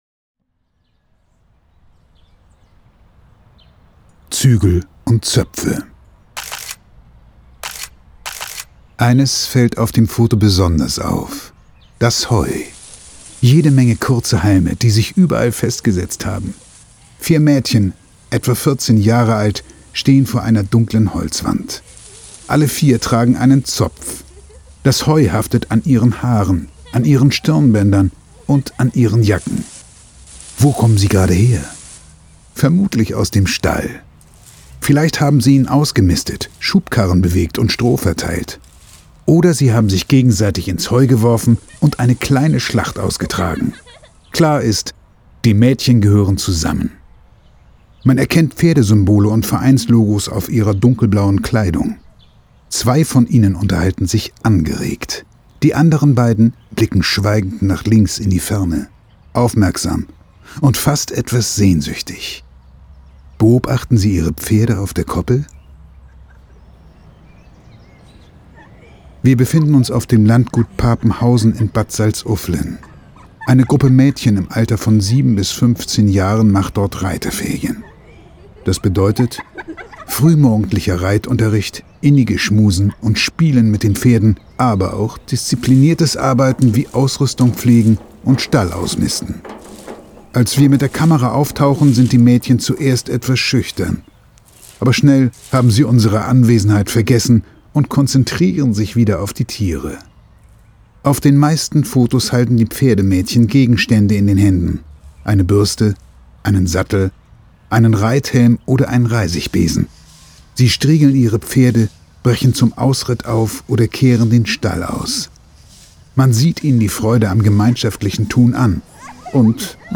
Unser Hörbuch genießen Sie am besten mit Kopfhörern .